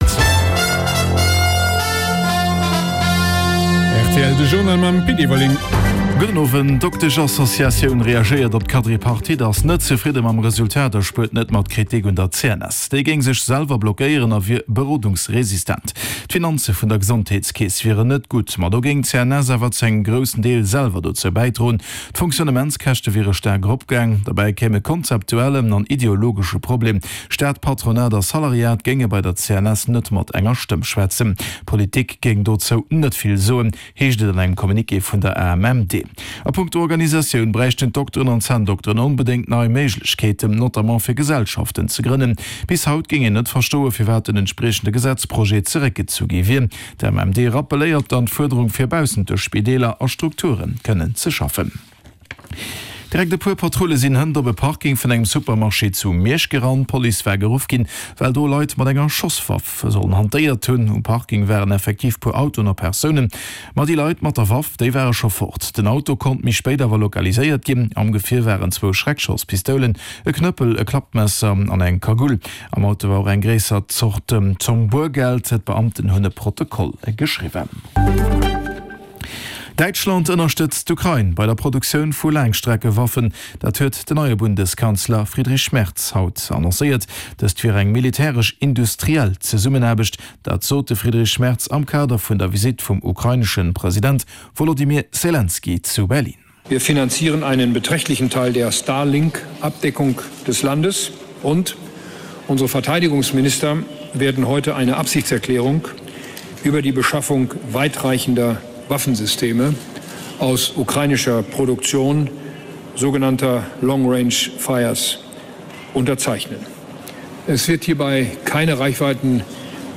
De grousse Journal vun RTL Radio Lëtzebuerg, mat Reportagen, Interviewën, Sport an dem Round-up vun der Aktualitéit, national an international